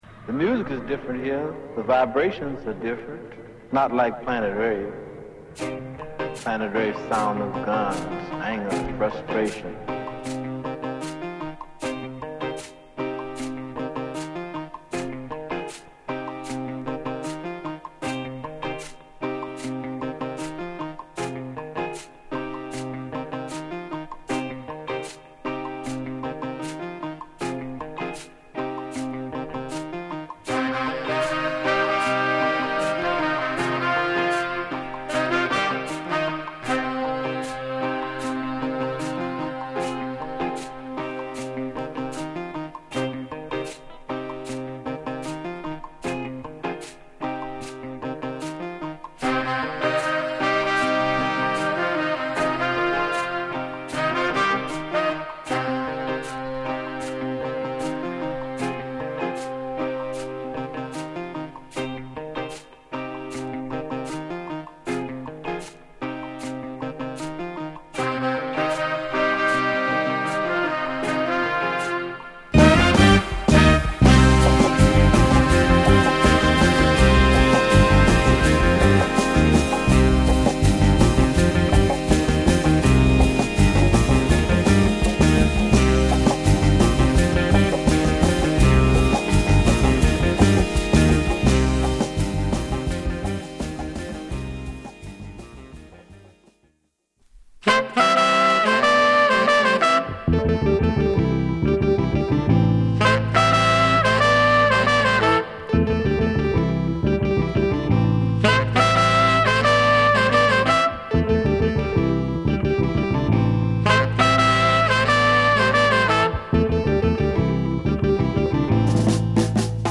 フリーキーなサックス・　ブロウも光るファンク・ジャズ色濃厚な
ワウも効いた疾走系アフロ・ファンク
リズミカルなギタープレイが光るナイジェリアン・ルーツ色濃厚な